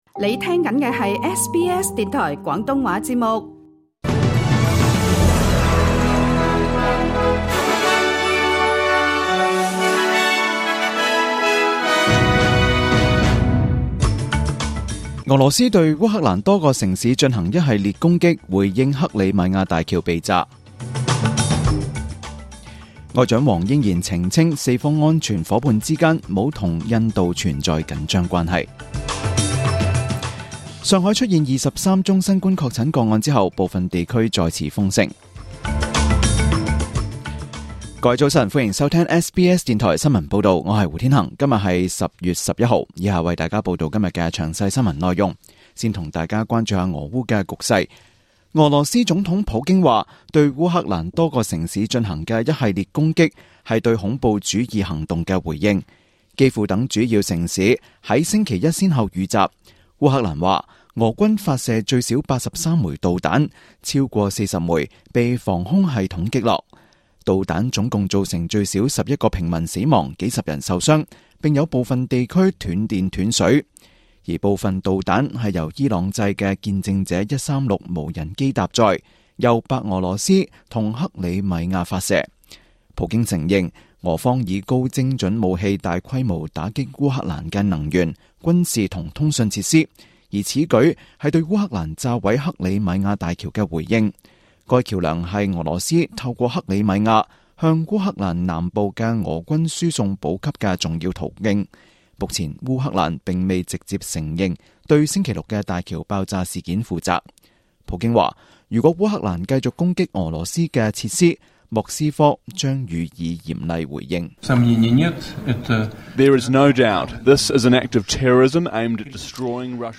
SBS 中文新聞 （10月11日）